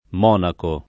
m0nako] s. m.; pl. ‑ci (antiq. ‑chi) — sim. i cogn. Monachi, ‑ci, ‑co, Del Monaco — così pure il top. Monaco: sia quello di Baviera (ted. München), sia il capoluogo del principato omonimo (fr. Monaco [monak1]) — cfr. Lo Monaco